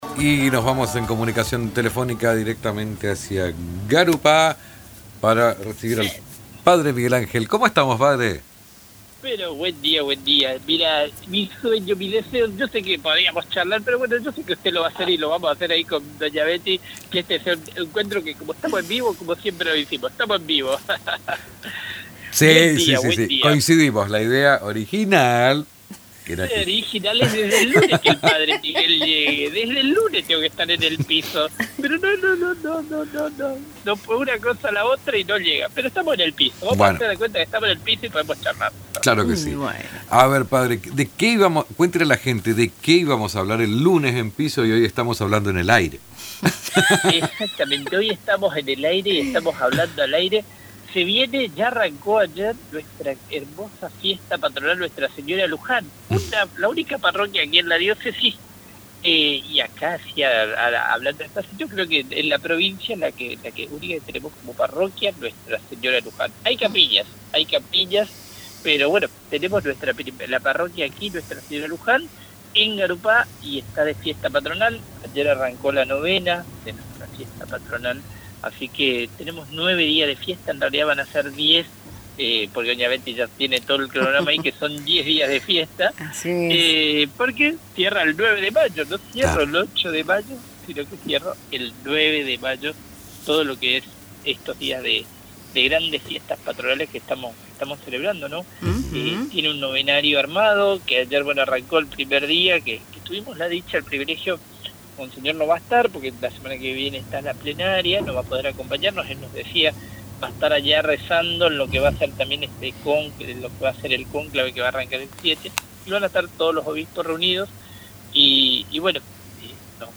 En diálogo telefónico con Radio Tupa Mbae